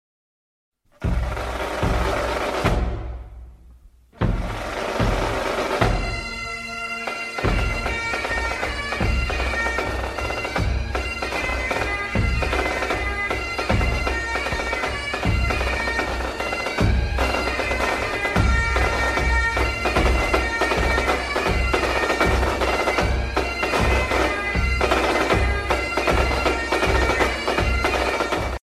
Classical Ringtones